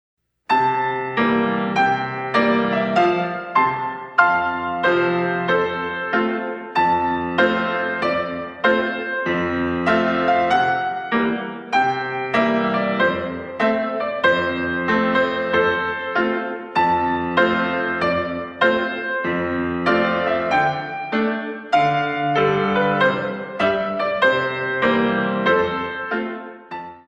64 Counts
Grand Battement